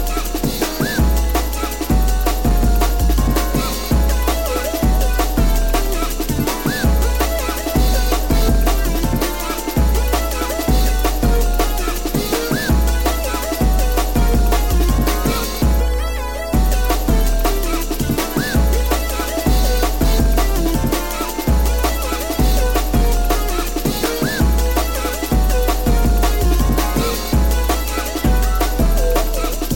TOP > Jungle